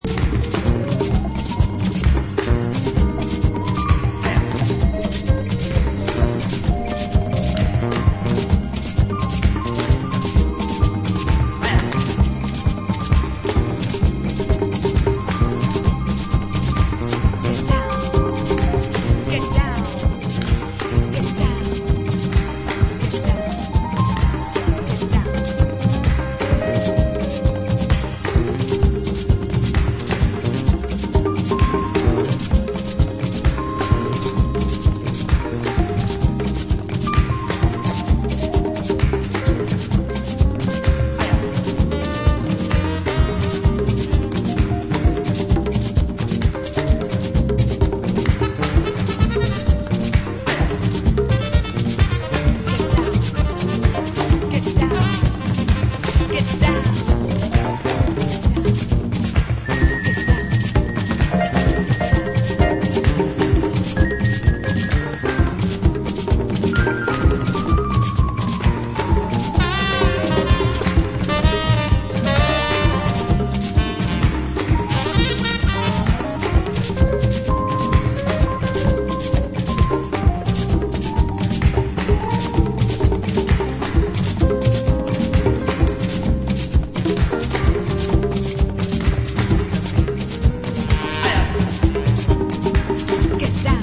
Disco Funk